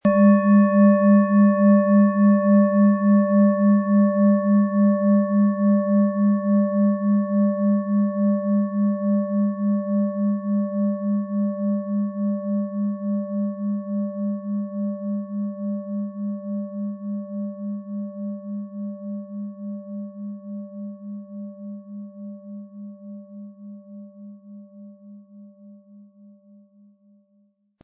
• Tiefster Ton: Uranus
MaterialBronze